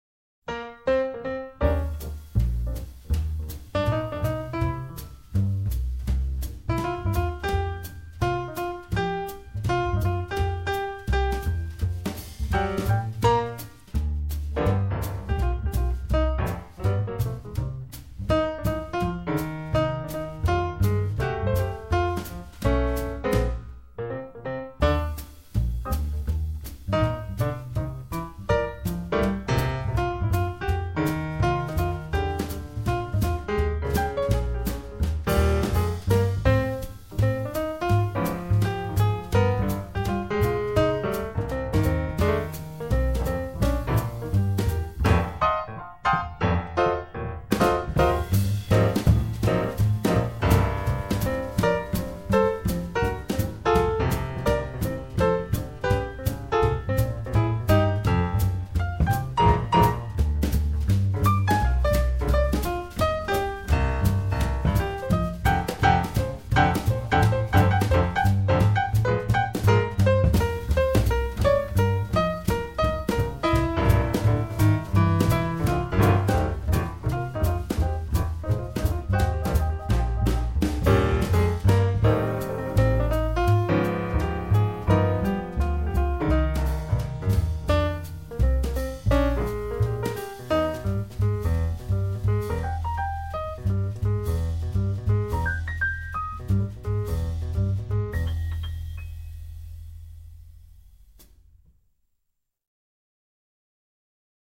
Instrumental Trio